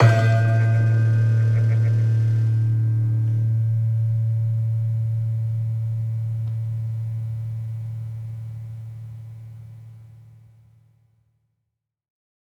Sound Banks / HSS-Gamelan-1 / Gender-1 / Gender-1-A1-f.wav
Gender-1-A1-f.wav